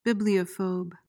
PRONUNCIATION:
(BIB-lee-uh-fohb)